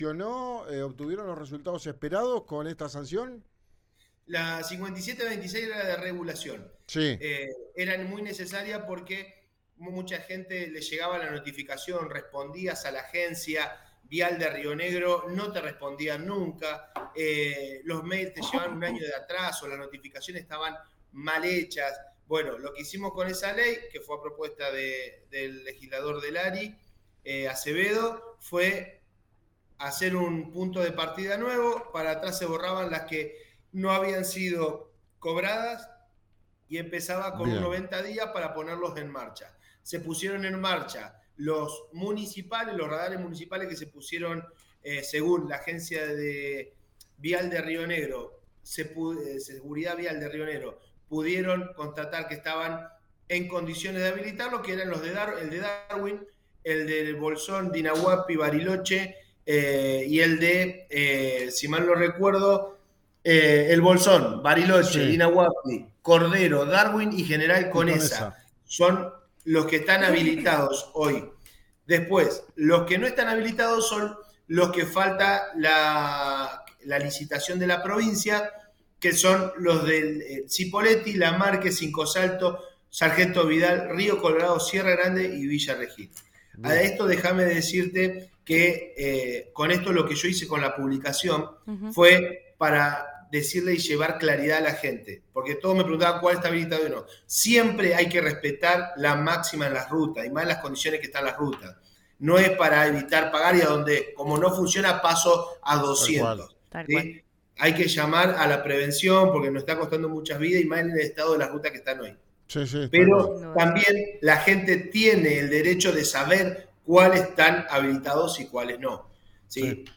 En diálogo con Radio RÍO NEGRO, enfatizó la necesidad de “reinterpretar” y dar nuevas opciones de seguridad vial.
Escuchá a Luciano Delgado Sempé, legislador de Vamos con Todos, en RÍO NEGRO RADIO: